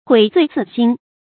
悔罪自新 注音： ㄏㄨㄟˇ ㄗㄨㄟˋ ㄗㄧˋ ㄒㄧㄣ 讀音讀法： 意思解釋： 悔改罪行，重新做人。